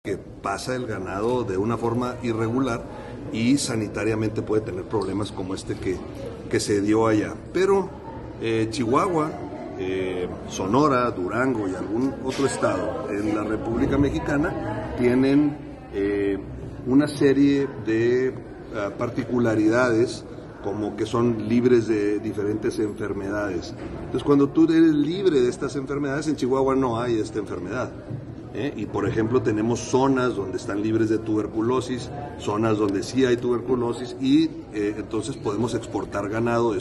AUDIO: EUGENIO BAEZA FUENTES, EMPRESARIO Y EX POLÍTICO